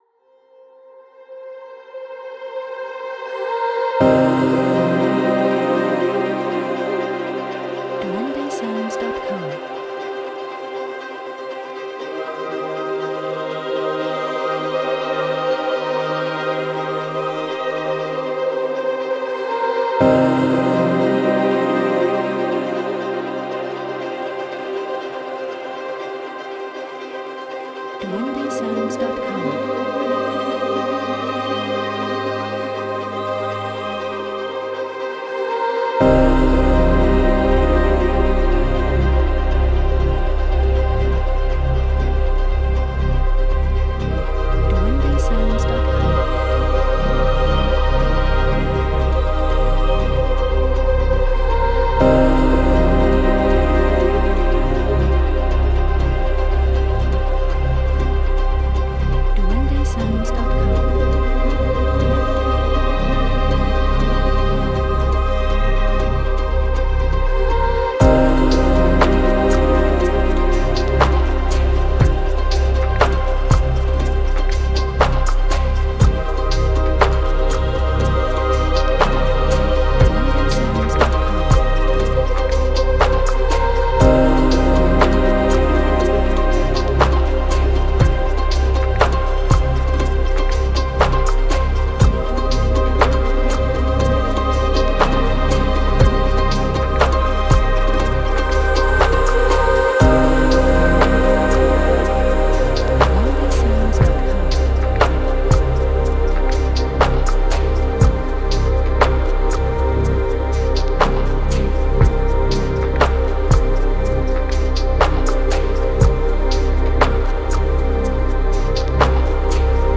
Positive;Sad;Thoughtful
Cinematic;Ambient